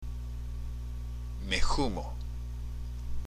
＜発音と日本語＞
（メ　フモ）